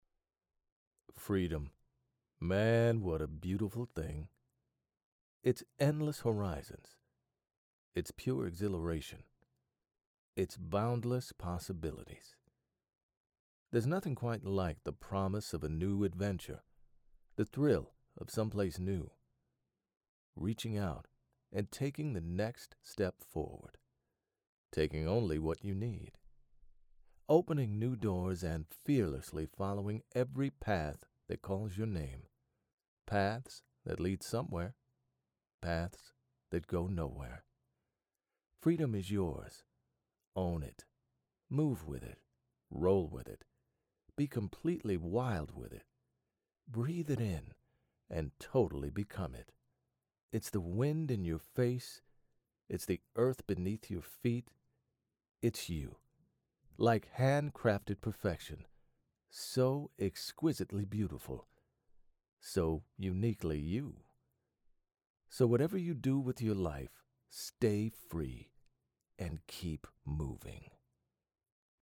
A Broadcast Professional with Decades of Commercial, Corporate, and Documentary Narration
Studio & Services: Professional home studio providing broadcast-quality audio with fast turnaround.
Documentary & Narration Demo